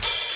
Open Hats
Boom-Bap Hat OP 78.wav